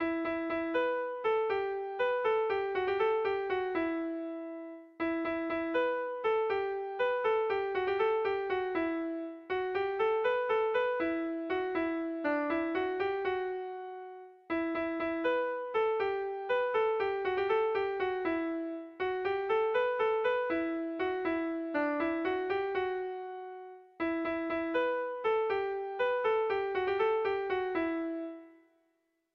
Sentimenduzkoa
Zortzi puntuko berezia
AABA